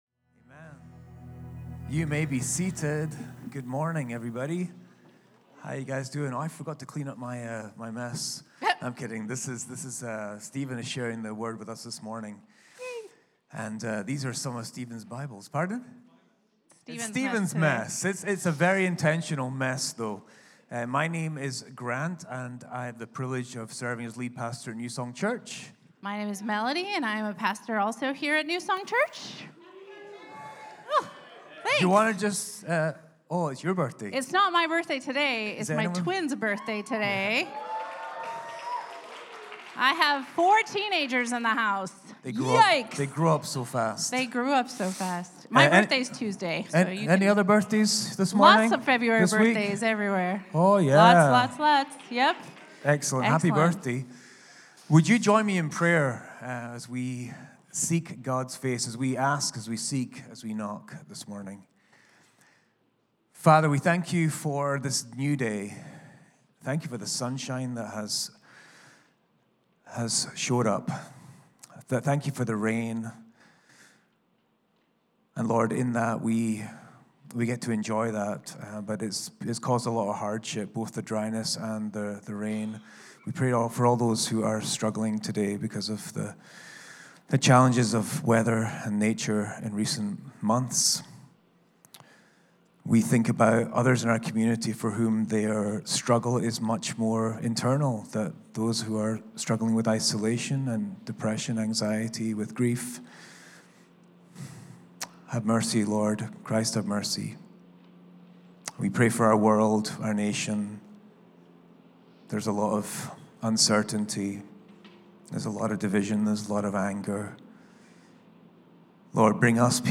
This Sunday, your pastors will reflect on the past year and prayerfully invite us to welcome the days God will give us in 2025.